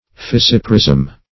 Search Result for " fissiparism" : The Collaborative International Dictionary of English v.0.48: Fissiparism \Fis*sip"a*rism\, n. [See Fissiparous .]